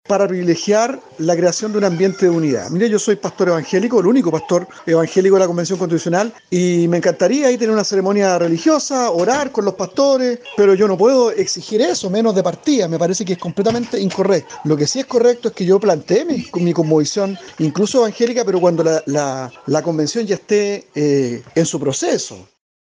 El convencional del distrito 20 y de la lista Vamos por Chile, Luciano Silva, dijo que espera que se genere un ambiente de unidad, sin intentar imponer ideologías.